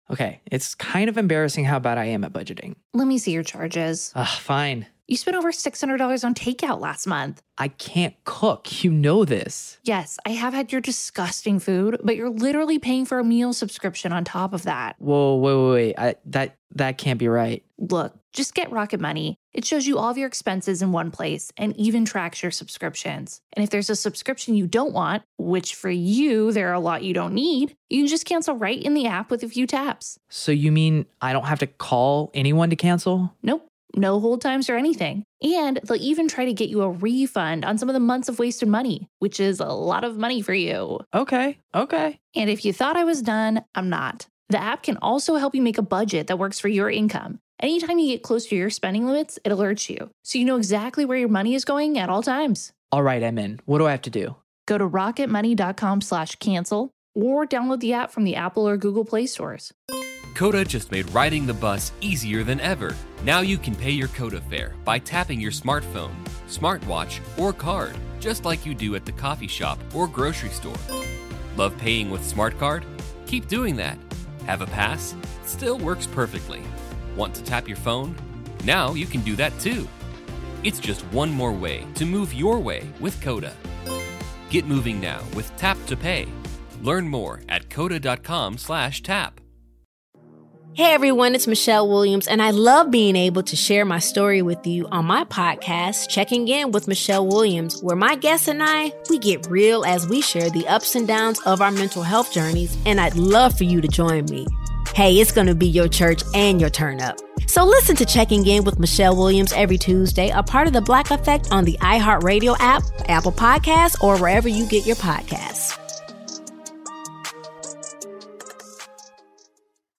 Listen back in Scott Ferrall's radio career in Part 1 of 2 of a show from October 98 where Scott talks about trades made in the NFL, the firings of the Espositio's in Tampa Bay and who he likes in College and Pro Football that week